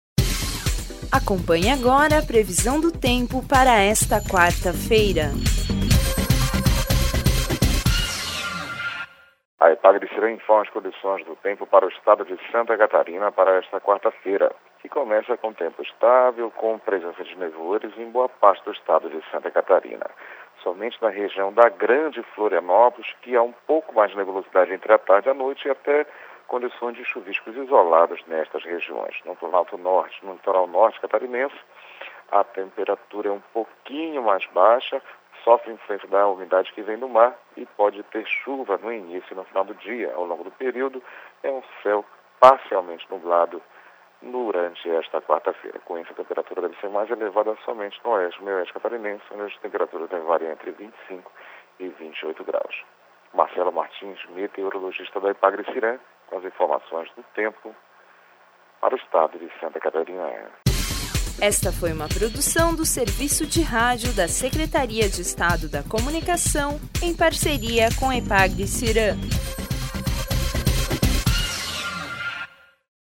Previsão do tempo para quarta-feira, 02/10/2013